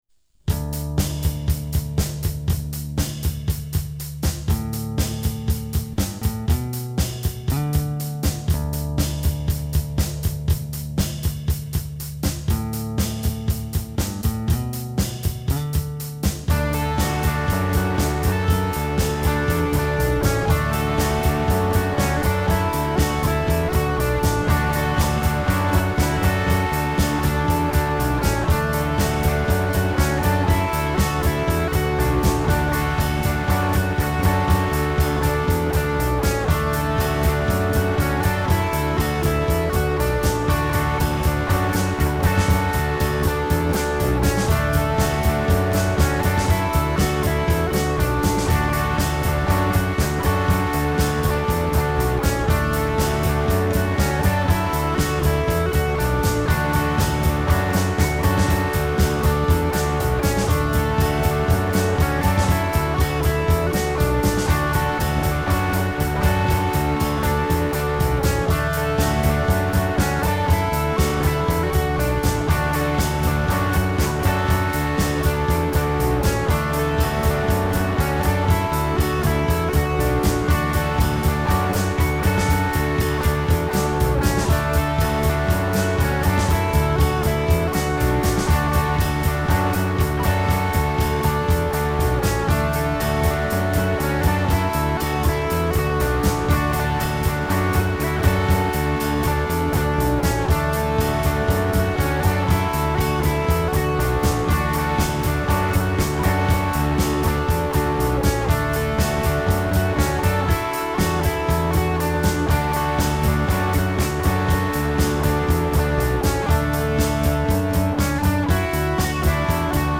self remix album
BOSSのドラムマシン大活躍。